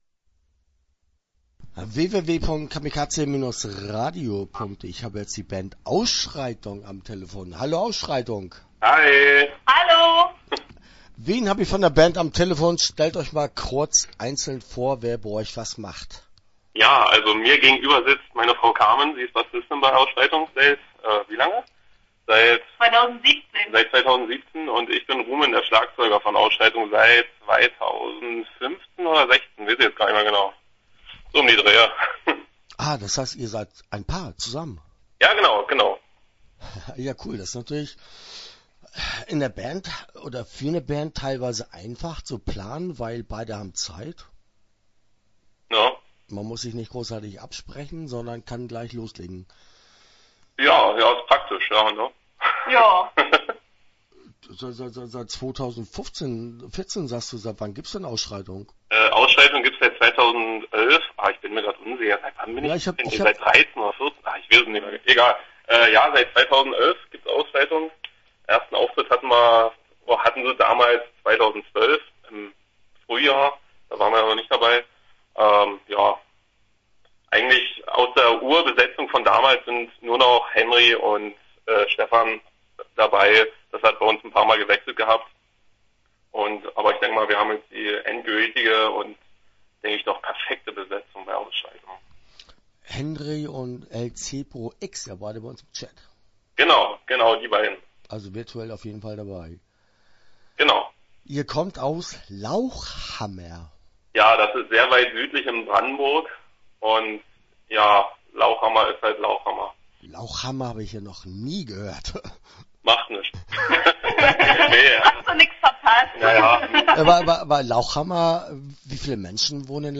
Start » Interviews » Ausschreitung